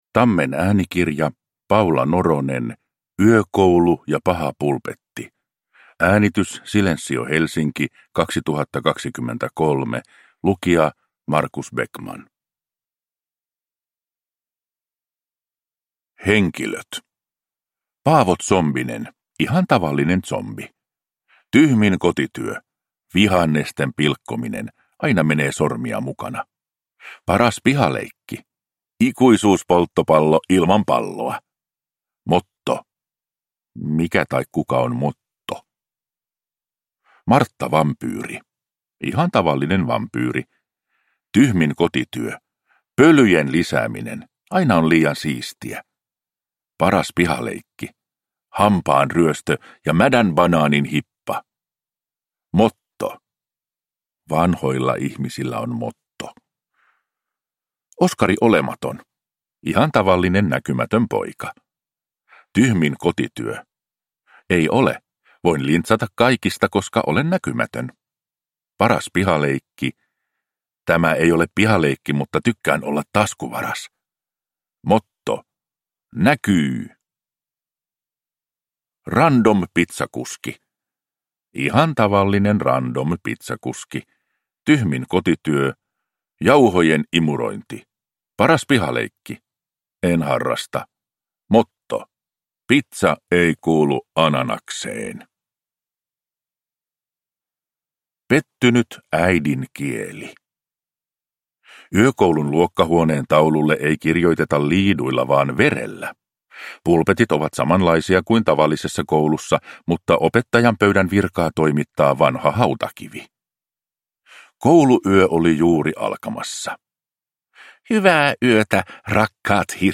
Yökoulu ja paha pulpetti – Ljudbok